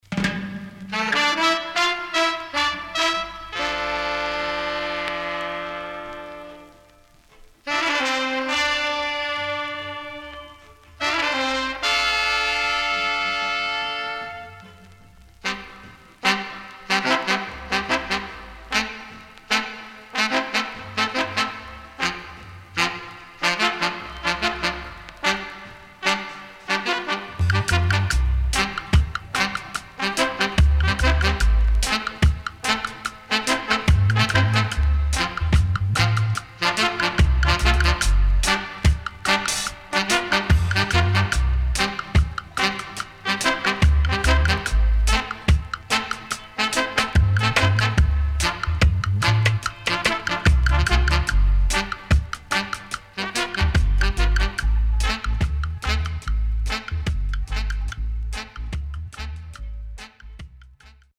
Rare.マイナー調 渋Roots.イントロのKillerなHornがインパクト大、情感豊かな歌いっぷりが素晴らしい1曲
SIDE A:最初1回プチノイズ入りますが良好です。